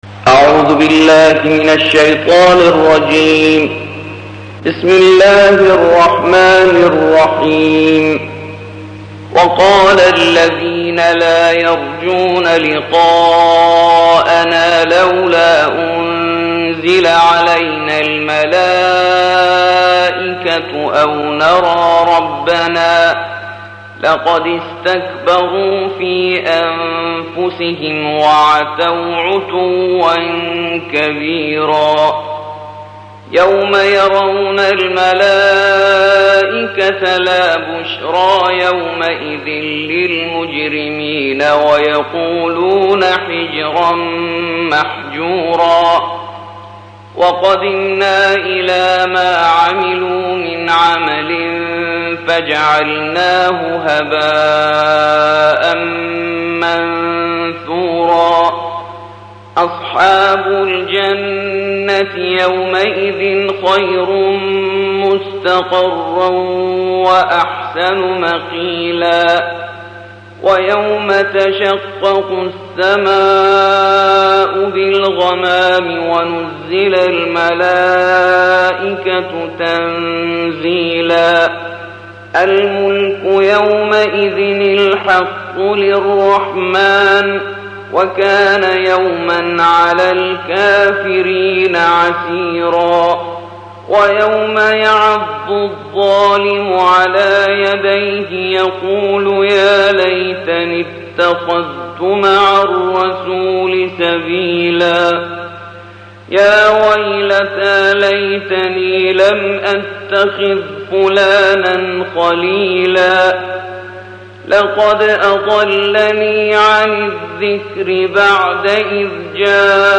الجزء التاسع عشر / القارئ